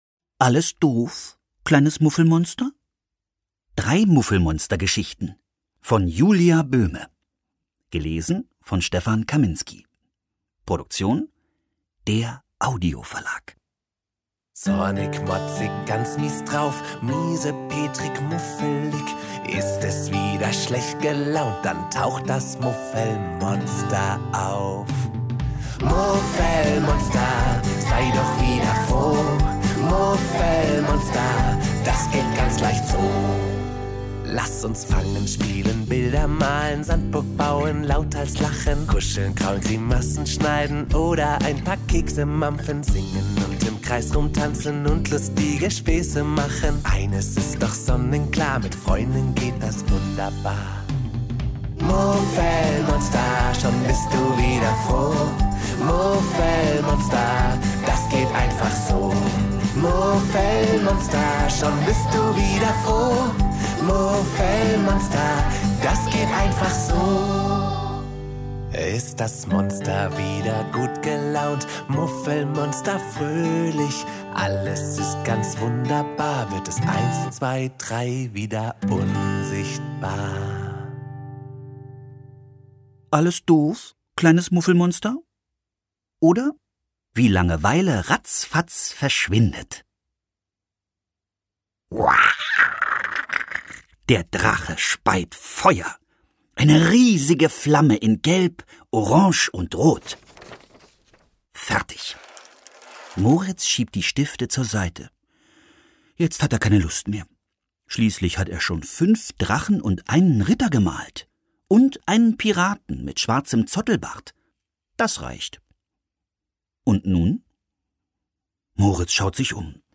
Stefan Kaminski (Sprecher)